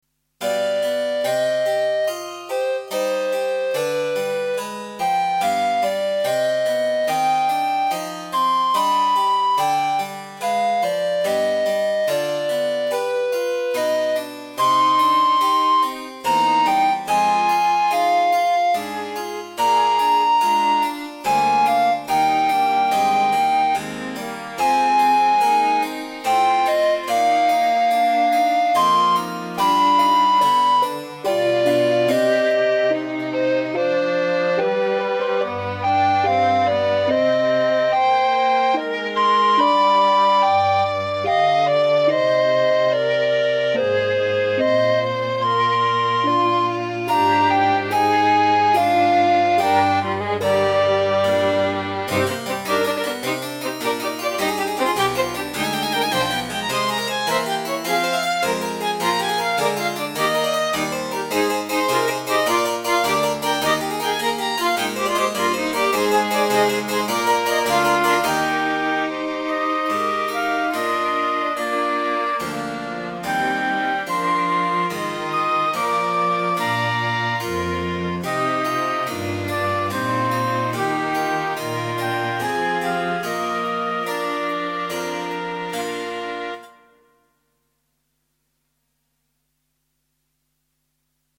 Een eenvoudige opzet van klavecimbel, fluit, cello, hobo, viool en altviool.
Ik hou van de rustieke, zondagse sfeer welke die muziek uit de recente oudheid uitstraalt.